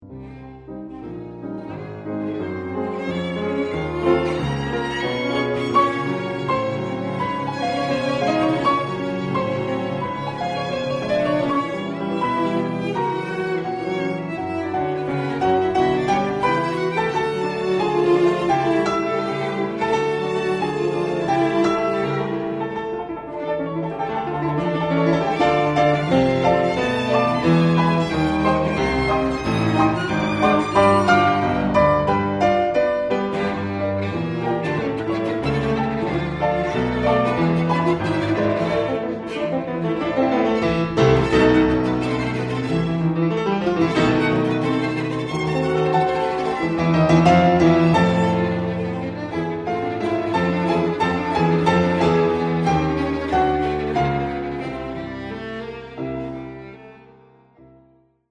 first world recording on period instruments